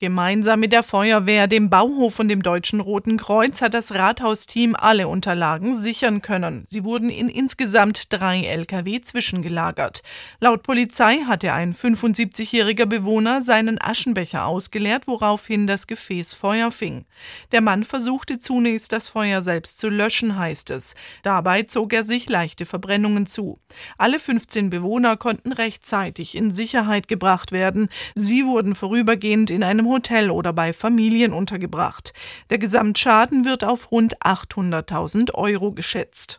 Damian Komor, Bürgermeister in Mainhardt
"Wir sind immer noch fassungslos", sagte Komor am Montag im SWR-Interview.